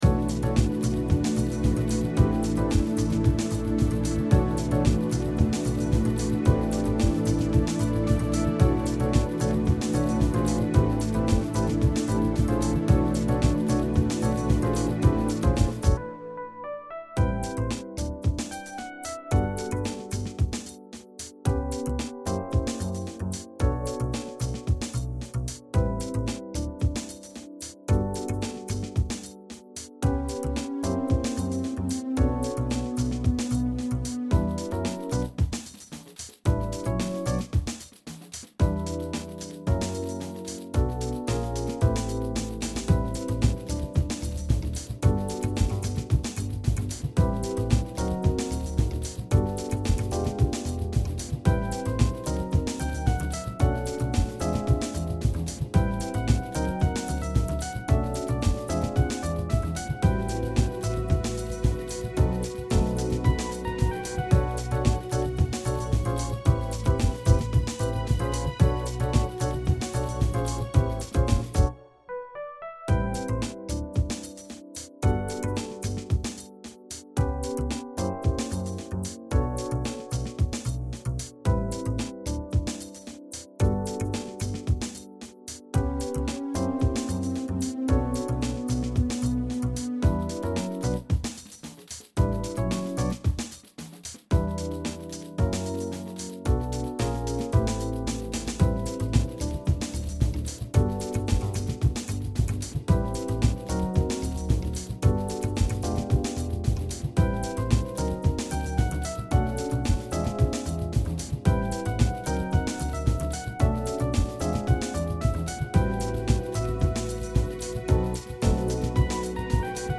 zum mitsingen